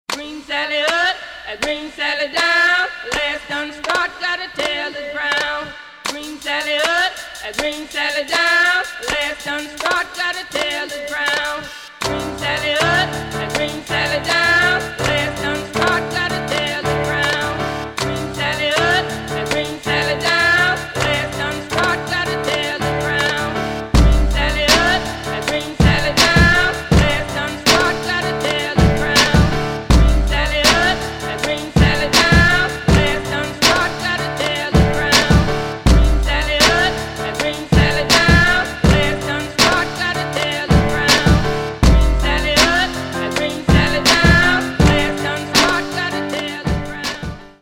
• Качество: 192, Stereo
indie rock